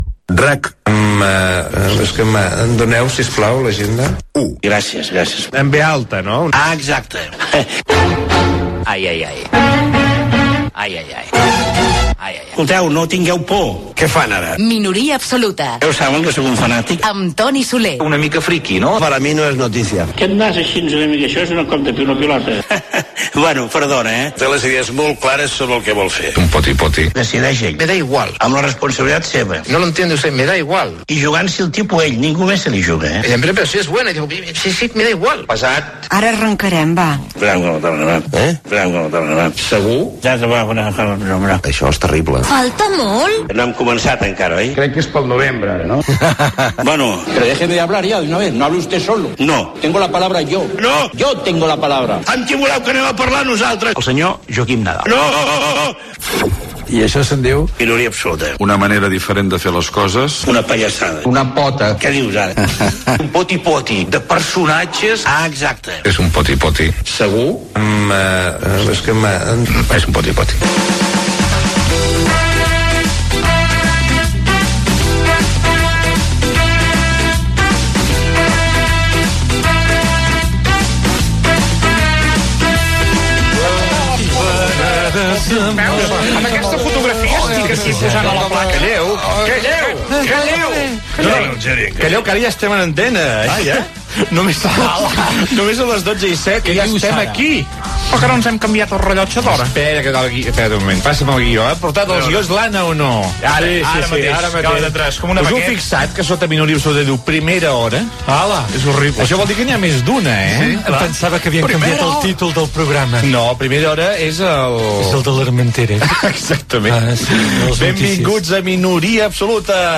bd937d9ebcd0506d9735f1575f7f0bc78e374888.mp3 Títol RAC 1 Emissora RAC 1 Barcelona Cadena RAC Titularitat Privada nacional Nom programa Minoria absoluta Descripció Careta del programa , diàleg inicial sobre que el programa passa a durar dues hores i els canvis que hi haurà, llista de personatges imitats. Trucada de Pasqual Maragall (imitació) al Palau de la Generalitat, el nou curs polític, "El racó poètic".